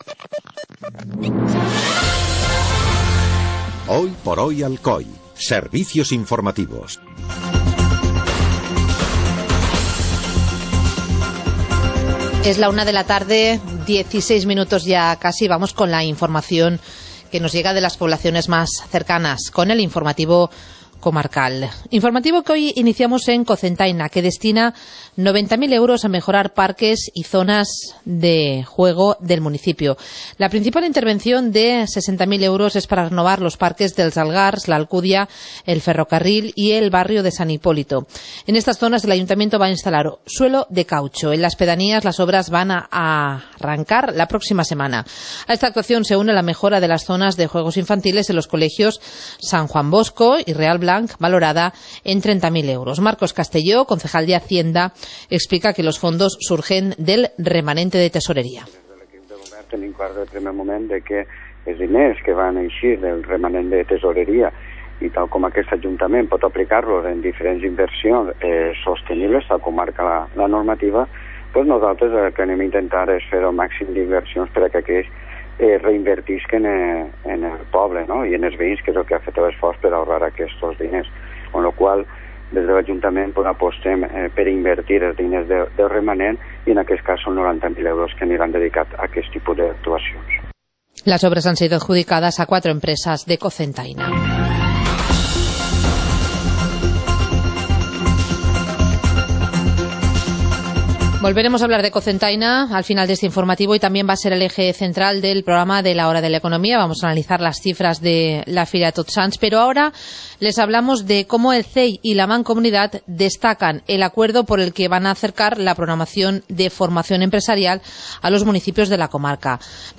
Informativo comarcal - martes, 28 de octubre de 2014